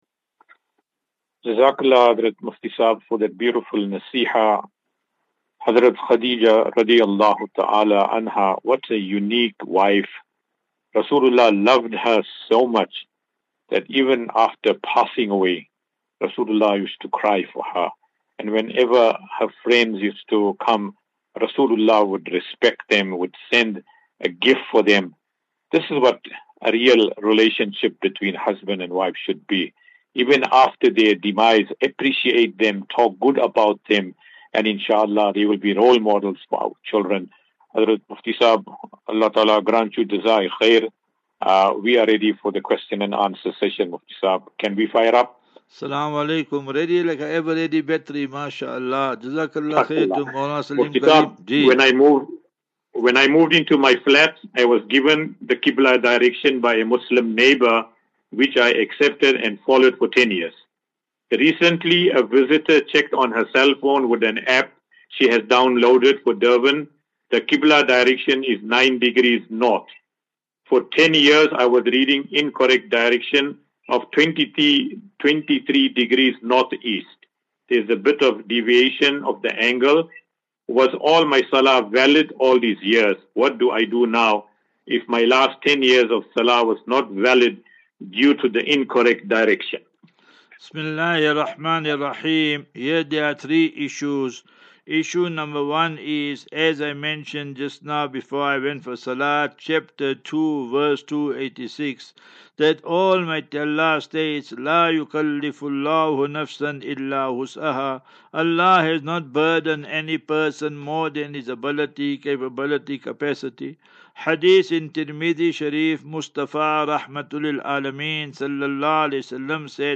As Safinatu Ilal Jannah Naseeha and Q and A 22 Mar 22 March 2024.